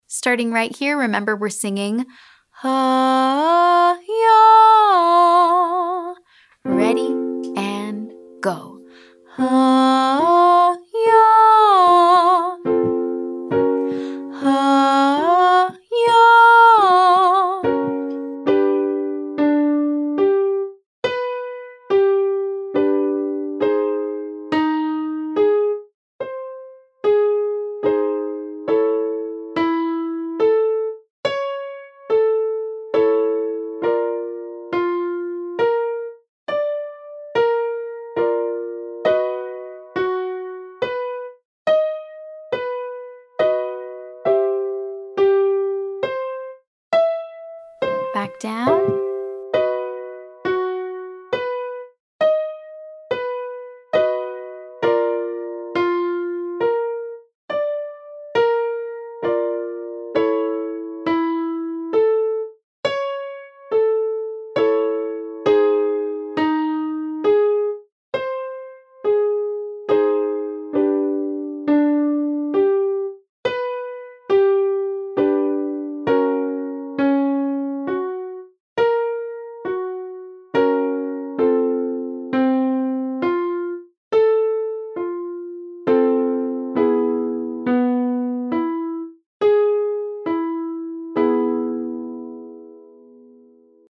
Exercise 3: Huh 5-8, Yah 10-8, up then down
For this next exercise we are going to use our Y glide for the process of finding a balanced mix very quickly on an unprepared high note.